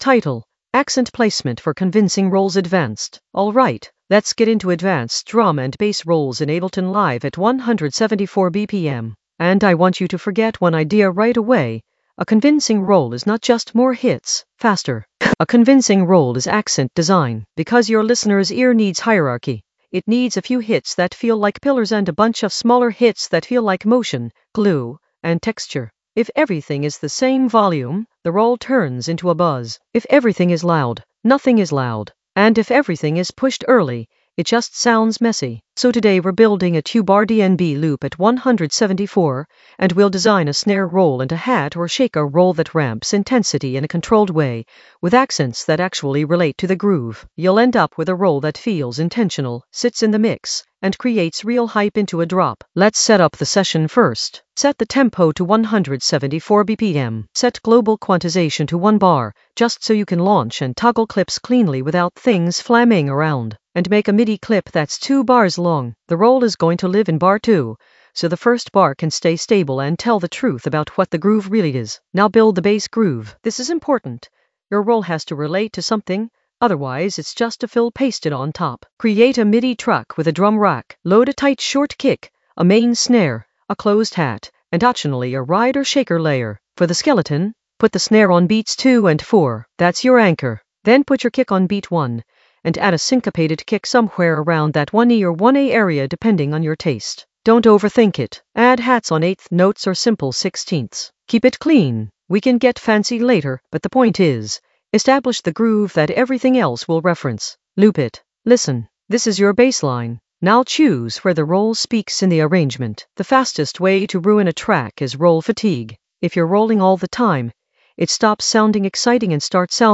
Narrated lesson audio
The voice track includes the tutorial plus extra teacher commentary.
accent-placement-for-convincing-rolls-advanced-groove.mp3